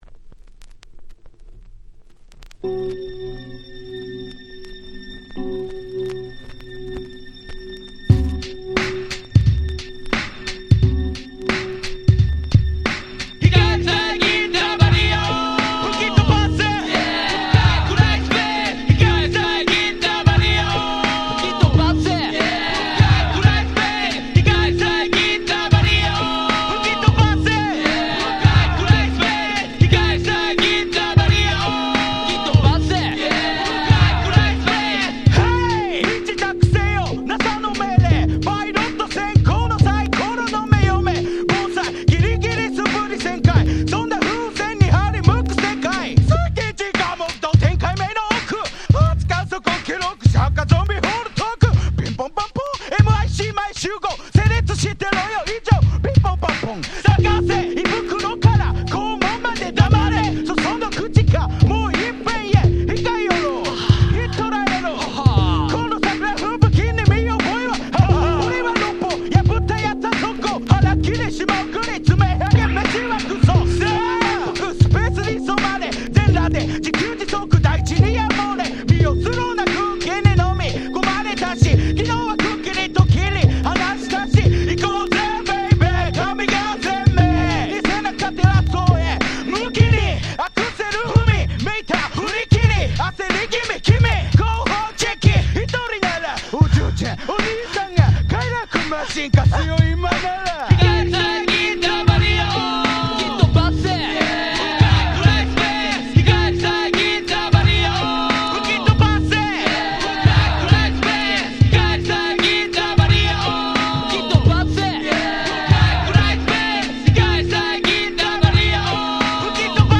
95' Japanese Hip Hop Classics !!
J-Rap Boom Bap ブーンバップ